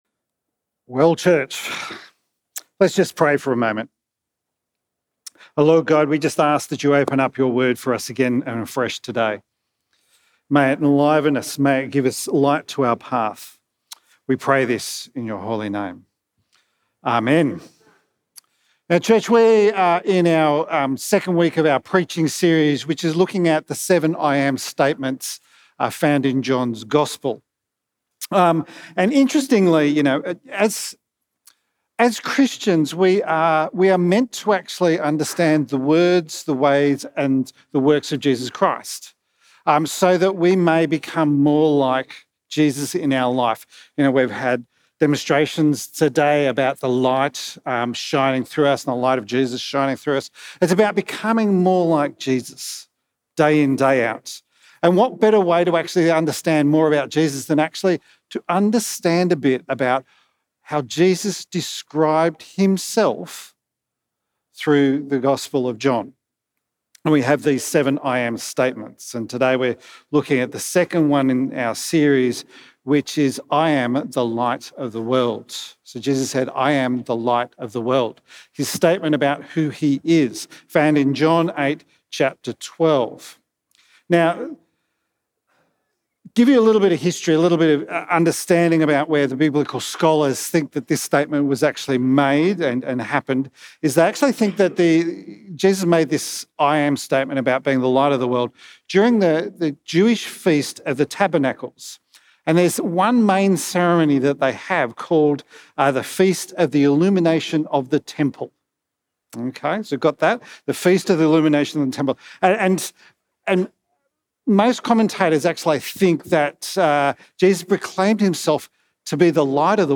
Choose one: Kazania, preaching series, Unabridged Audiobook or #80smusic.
preaching series